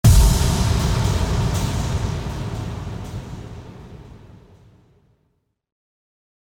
FX-1872-IMPACT
FX-1872-IMPACT.mp3